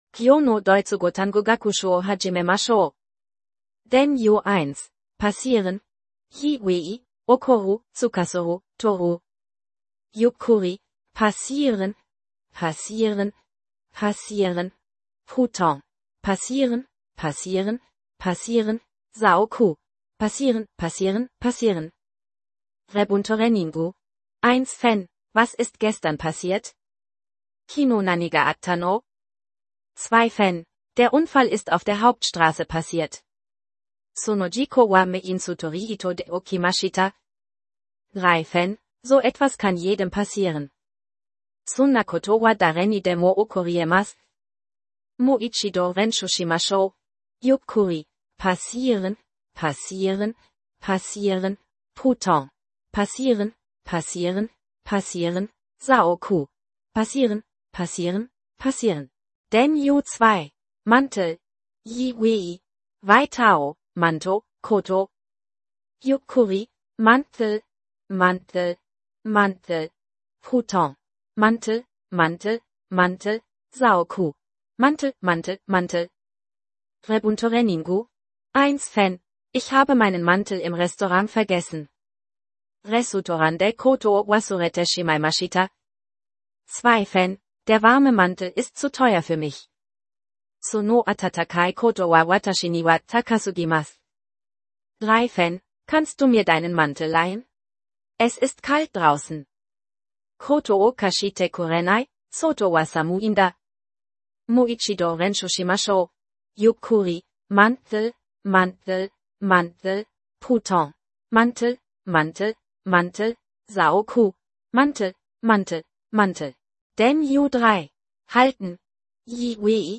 ゆっくり: passieren… passieren… passieren 普通: passieren, passieren, passieren 早口: passieren-passieren-passieren